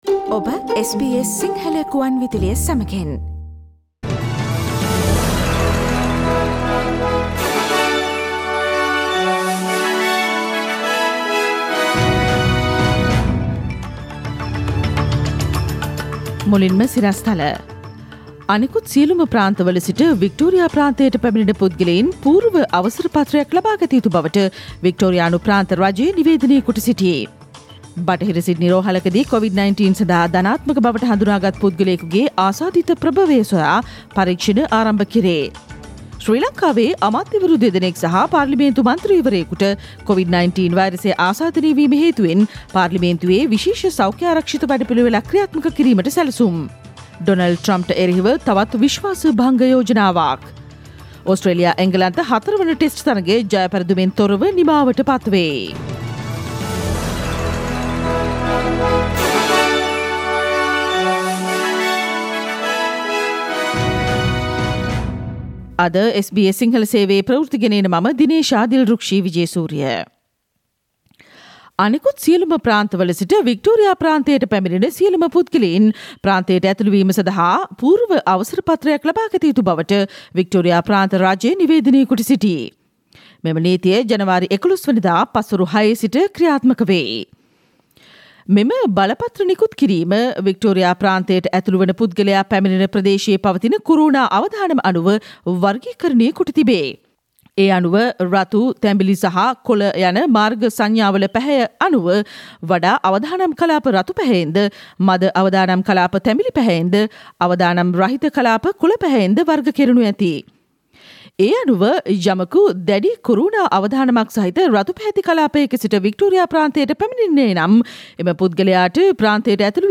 Today’s news bulletin of SBS Sinhala radio – Tuesday 12 January 2021